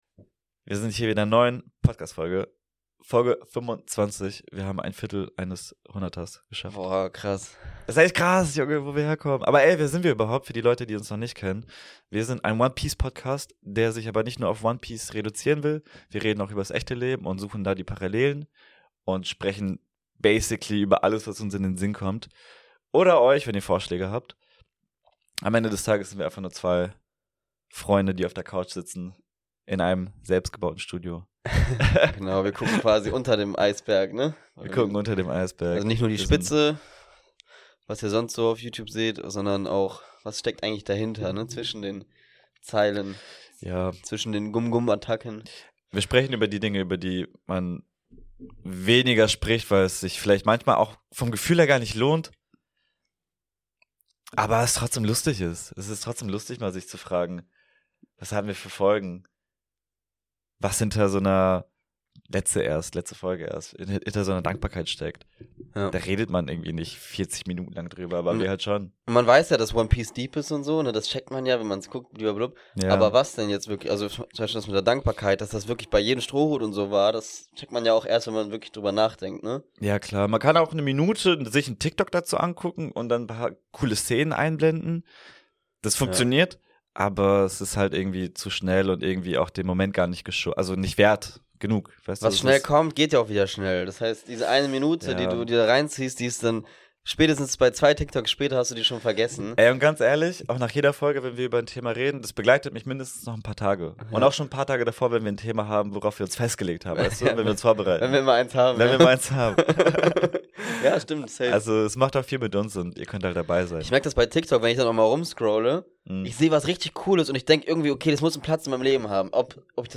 In dieser Episode wird es locker, ehrlich und ungefiltert. Kein festes Thema, kein Skript – einfach zwei Köpfe, die über Fortschritt, KI, Zukunft, Entwicklung und das Leben allgemein sprechen. Wir reden darüber, wie schnell sich die Welt verändert, welche Chancen Künstliche Intelligenz mit sich bringt, warum persönlicher Fortschritt heute wichtiger ist als je zuvor und wie man in einer Zeit voller Ablenkung trotzdem seinen eigenen Weg geht.